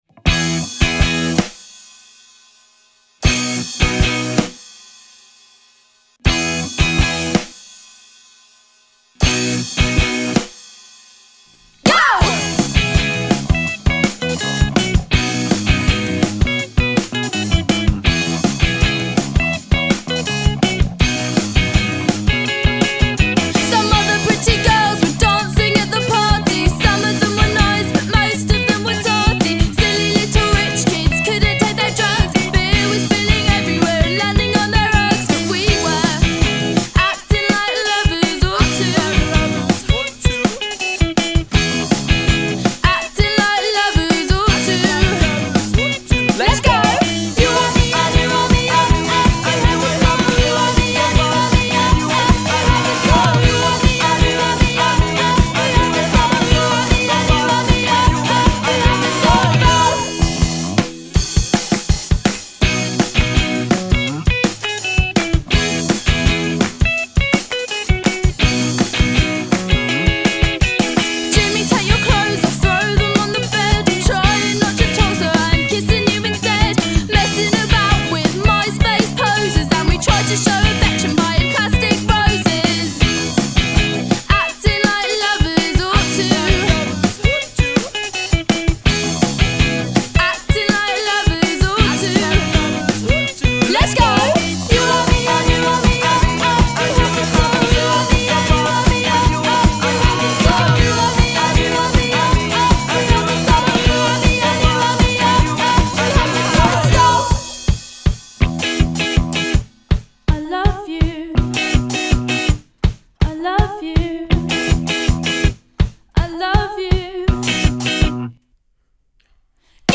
indiepop
Tre voci a rincorrersi